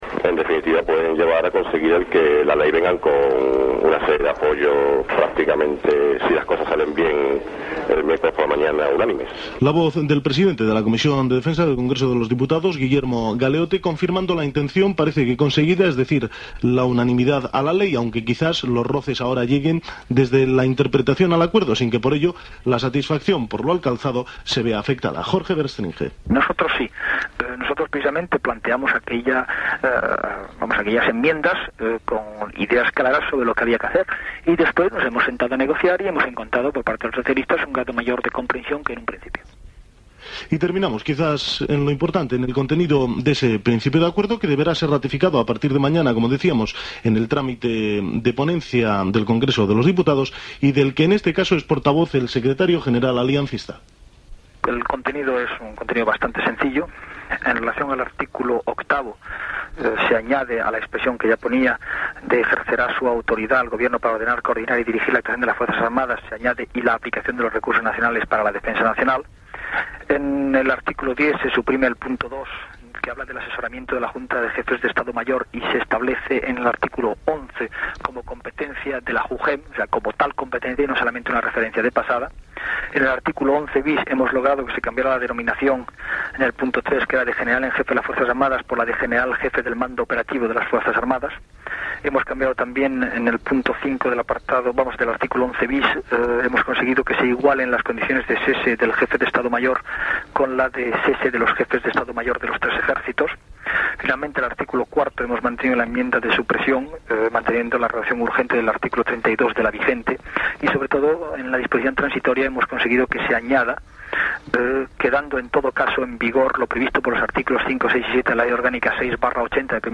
Informació sobre els acords de la Reforma de la Ley Orgánica 6/1980, de 1 de juliol, per la que es regulen els criteris bàsics de la defensa nacional i l'organització militar. Declaracions de Jorge Verstrynge, portaveu d'Alianza Popular.
Informatiu